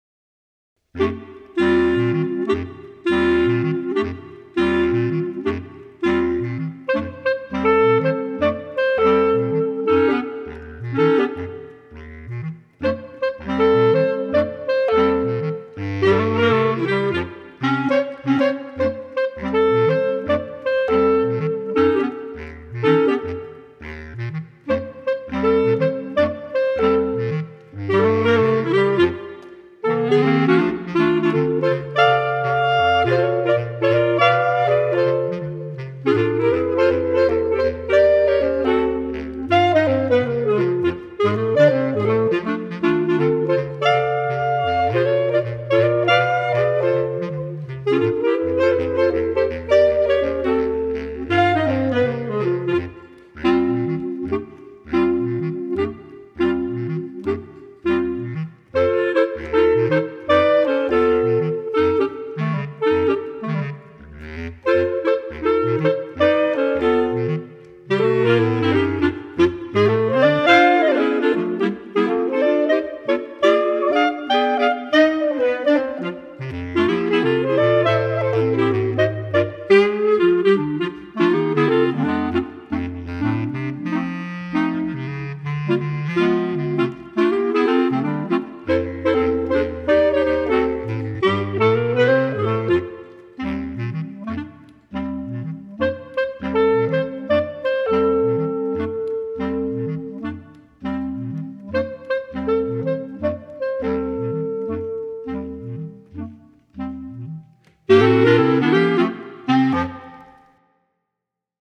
Set in a Calypso style